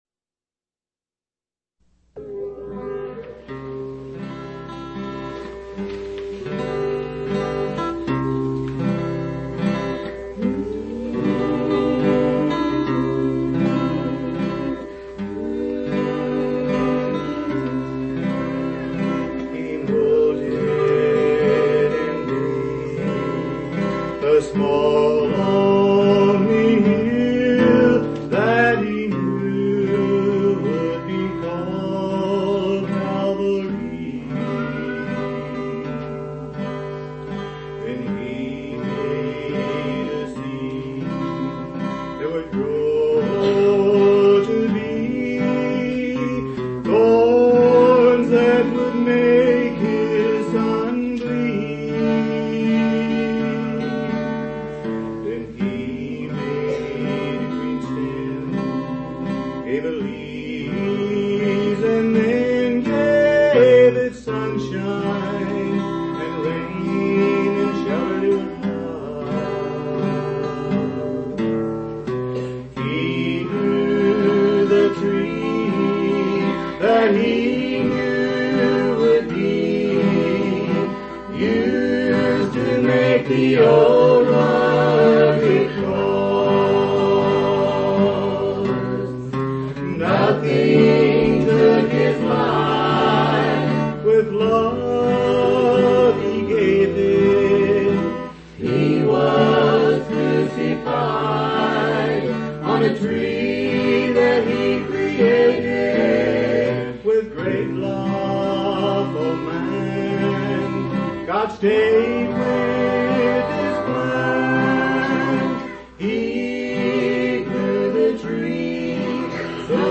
11/24/1984 Location: Phoenix Reunion Event